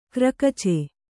♪ krakace